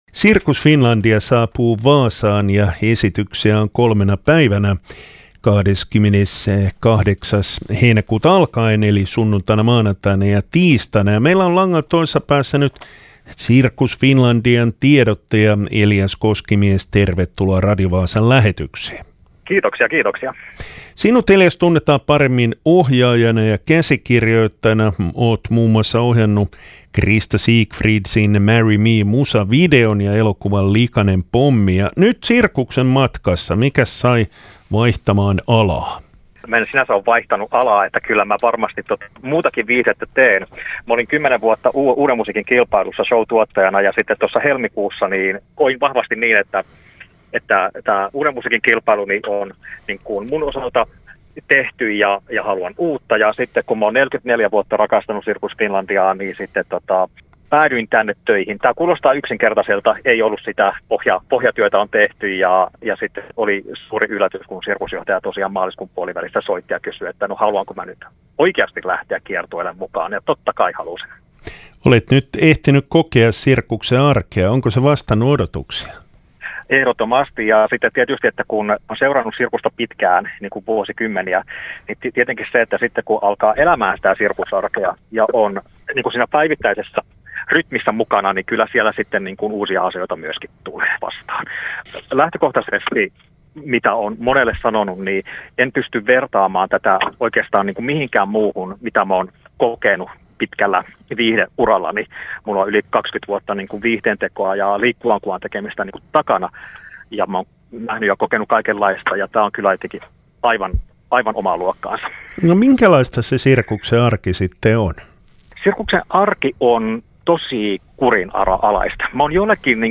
haastattelussa